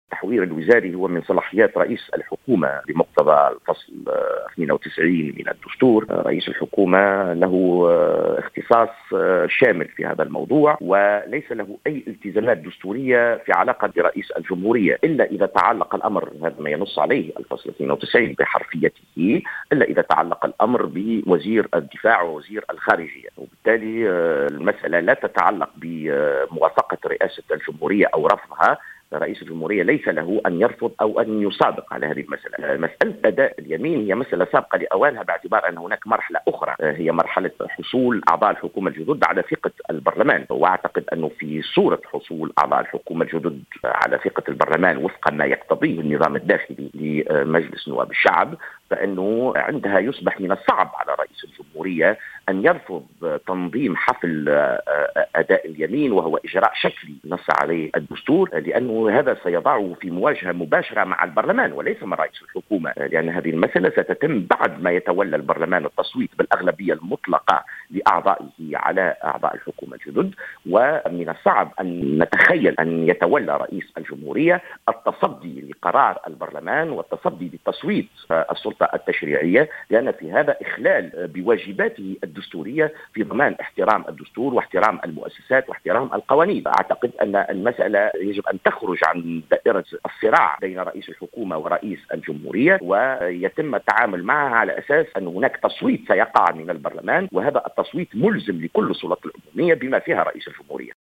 قال أستاذ القانون الدستوري جوهر بن مبارك في تصريح للجوهرة "اف ام" اليوم الثلاثاء أن التحوير الوزاري هو من صلاحيات رئيس الحكومة بمقتضى الفصل 92 من الدستور و له اختصاص شامل في هذا الخصوص.